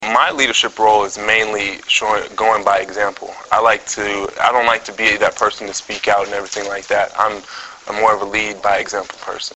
Preseason Press Conference
Memorial Stadium - Lincoln, Neb.